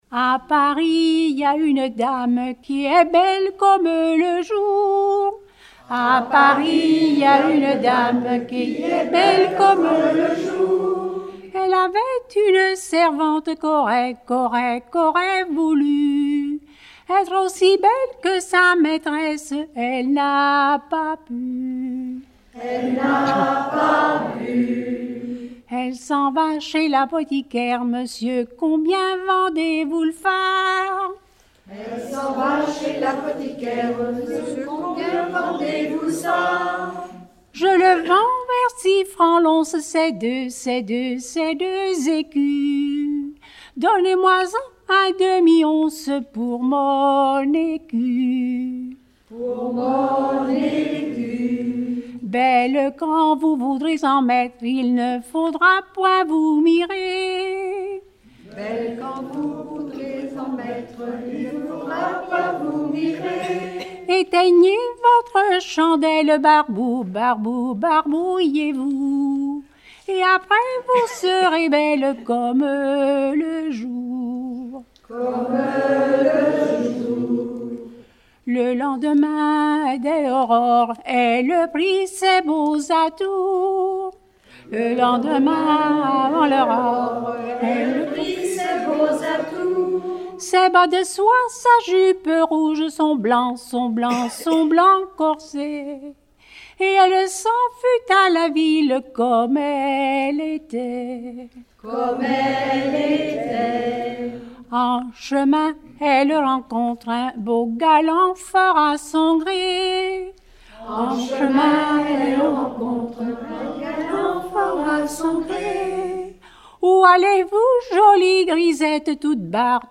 Bocage vendéen
Genre strophique
Pièce musicale éditée